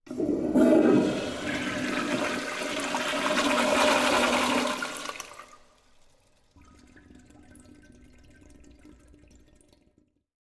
Звуки смыва унитаза
Шум воды в унитазе